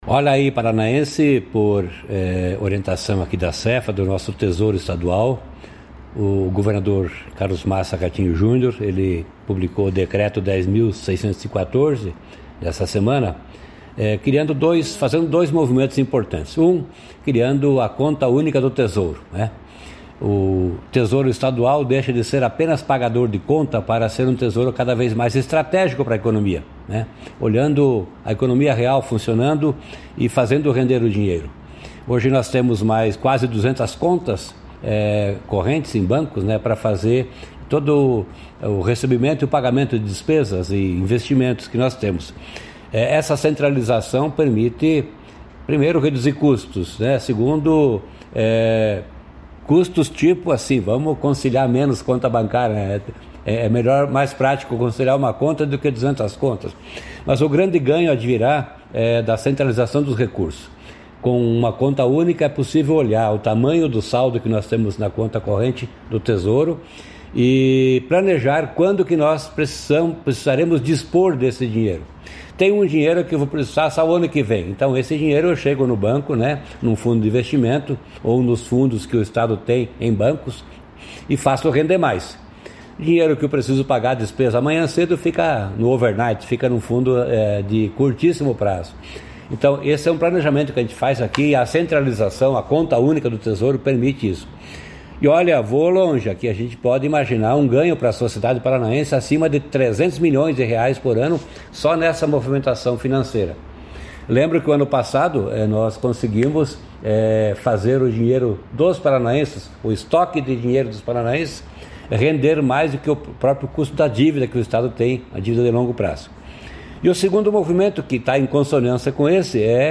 Sonora do secretário da Fazenda, Norberto Ortigara, sobre a adoção da Conta Única e Limite de Saque para modernizar e otimizar gestão financeira